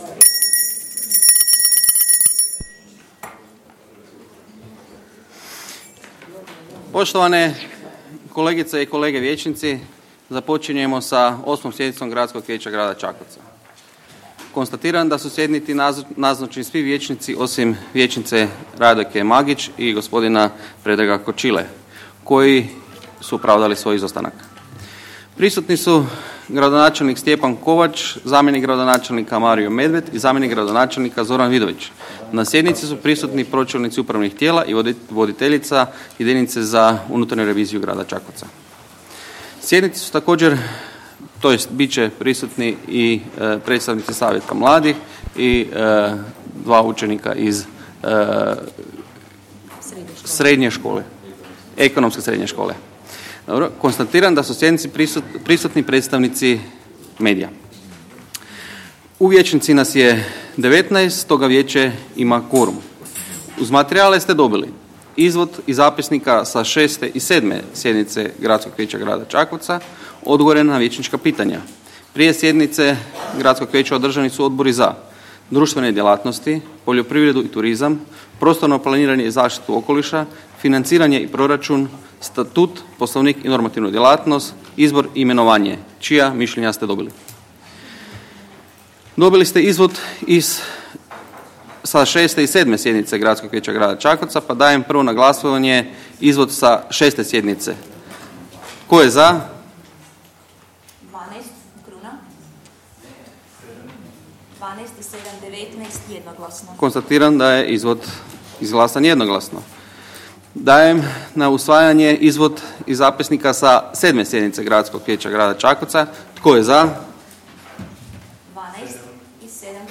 Održana 8 sjednica Gradskog vijeća Grada Čakovca - Grad Čakovec
Sjednica je održana 18. listopada 2018. s početkom u 12.00 sati sa sljedećim:
Audio zapisnik 8. sjednice Gradskog vijeća